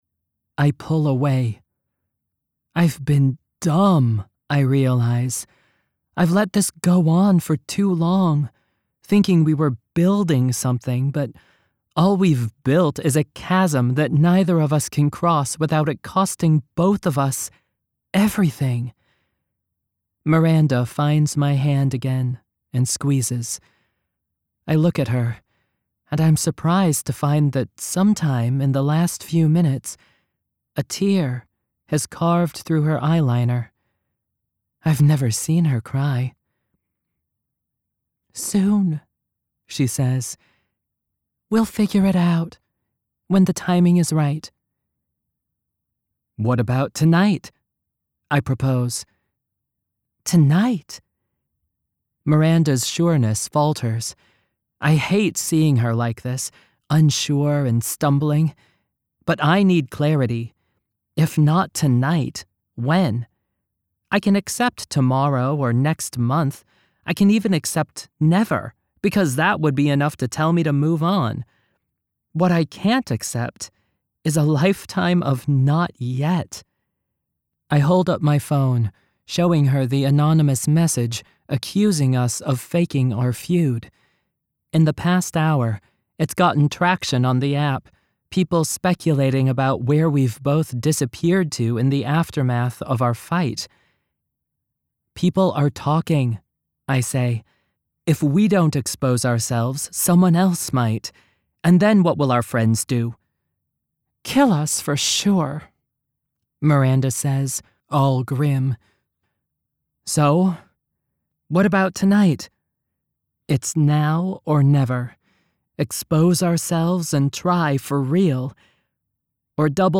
Narration Samples
1st Person
Non-binary teen & teen girl